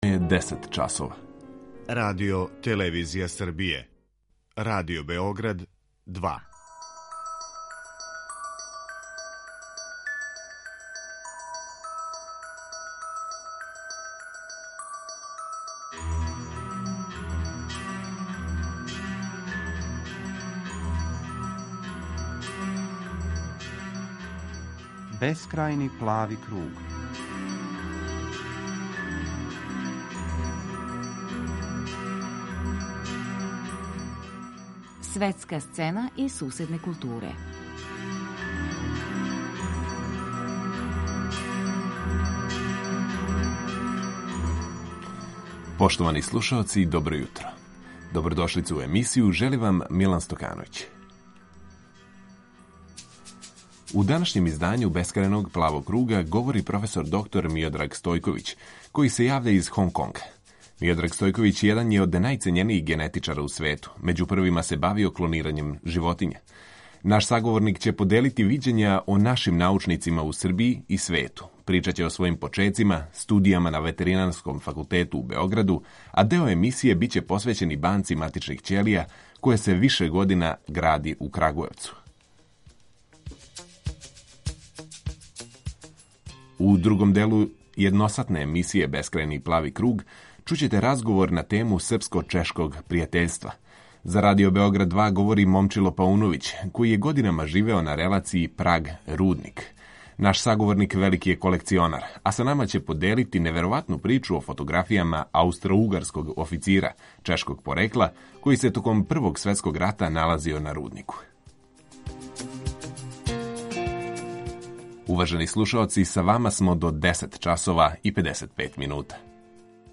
У данашњем издању Бескрајног плавог круга говори професор доктор Миодраг Стојковић, који се јавља из Хонг Конга.